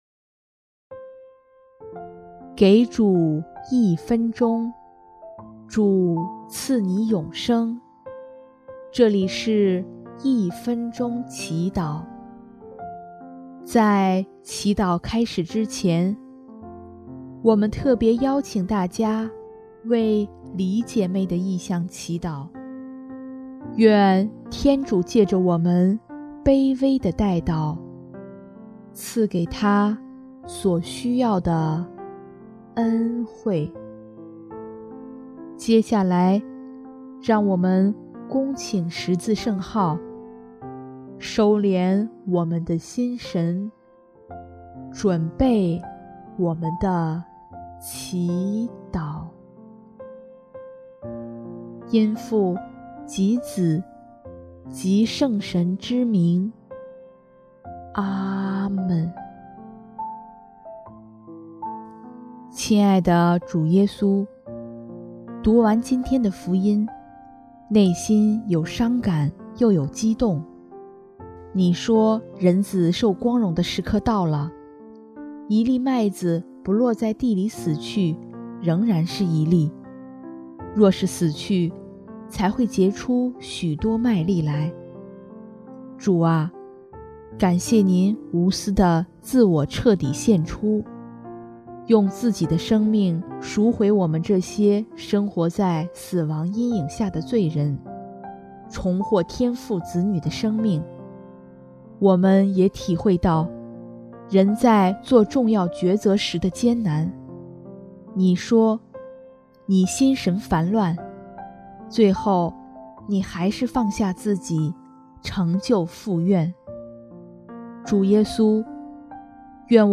音乐： 第三届华语圣歌大赛参赛歌曲《献上一切》